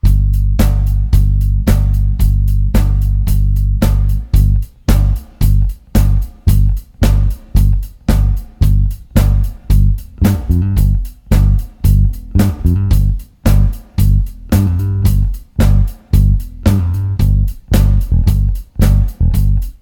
Guitare Basse